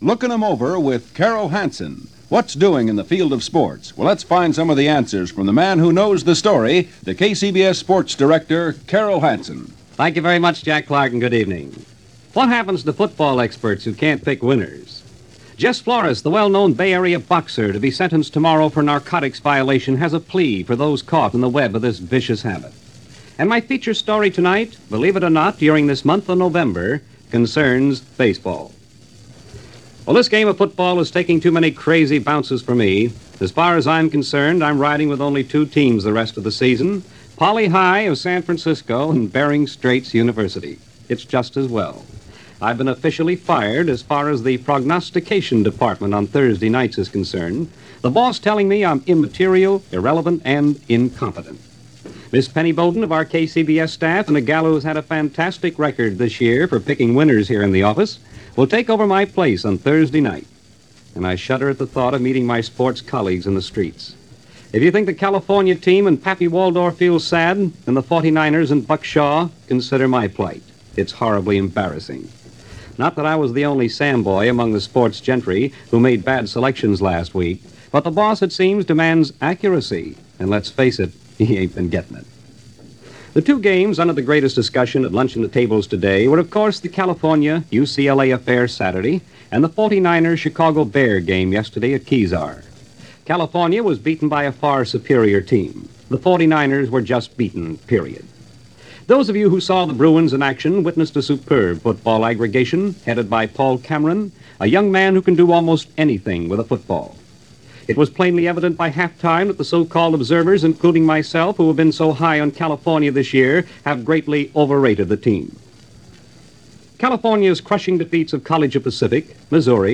Sometime around Thanksgiving 1952 and it’s smack in the middle of College Football season and this daily Sports feature from KCBS-Radio in San Francisco gives the rundown on College teams on the West Coast as well as an across-the-board look at the Sports scene as it was happening in the Bay Area.